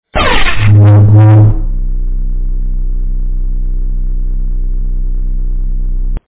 Download Lightsaber Clash sound effect for free.
Lightsaber Clash